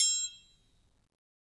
Triangle3-HitM_v1_rr2_Sum.wav